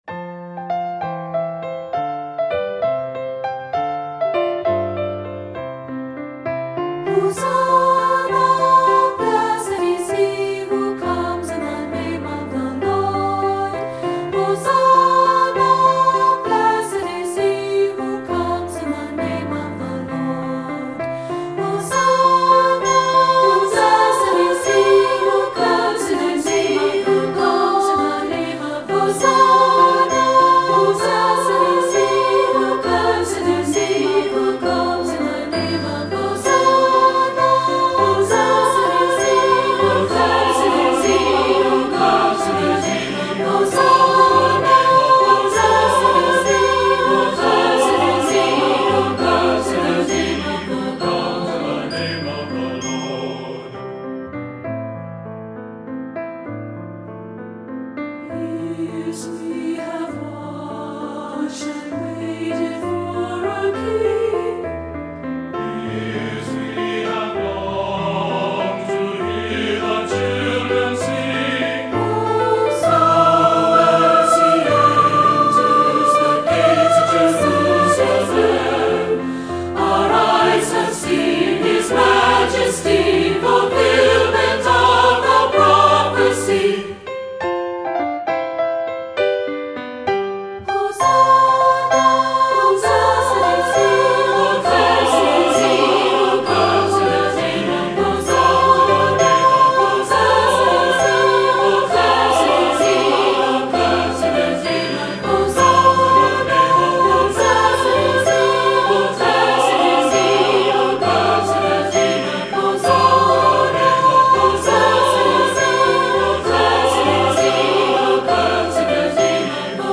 Voicing: 3-Part